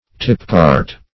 Search Result for " tipcart" : The Collaborative International Dictionary of English v.0.48: Tipcart \Tip"cart`\, n. A cart so constructed that the body can be easily tipped, in order to dump the load.